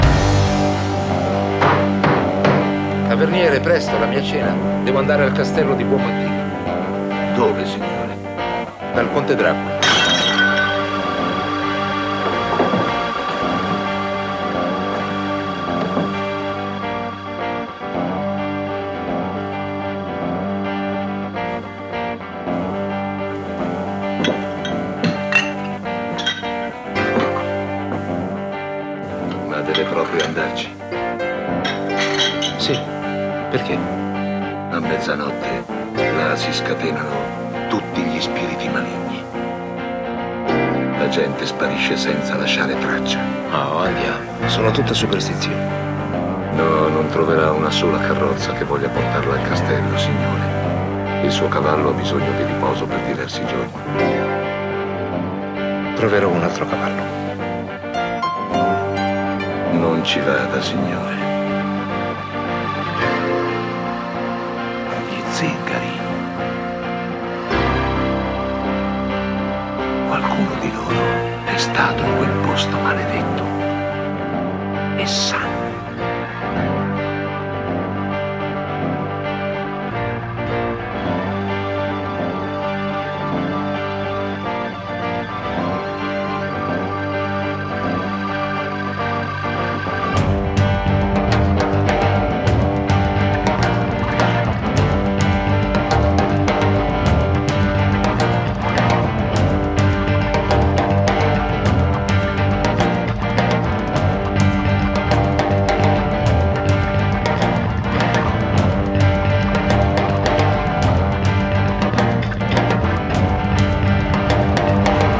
Italian prog band
melodic progressive concept albums